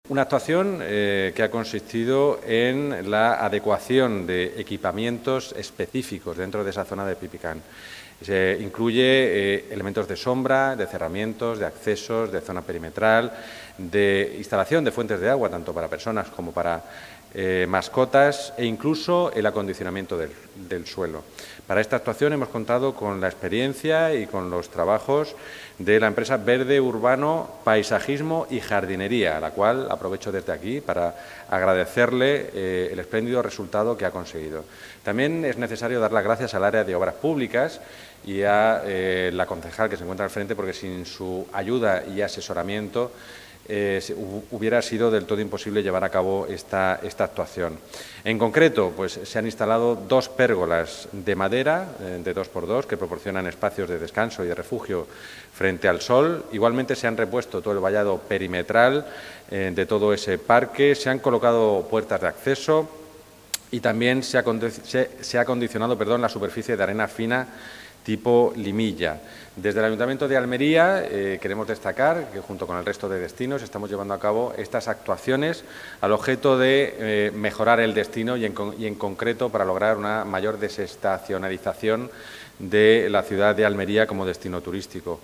El concejal de Turismo, Comunicaciones y Promoción de la Ciudad, Joaquín Pérez de la Blanca, ha detallado en rueda de prensa que las obras se han financiado a través del programa ‘Experiencias Turismo España’, enmarcado en el Plan de Recuperación, Transformación y Resiliencia, con una inversión de 14.811,80 euros más IVA.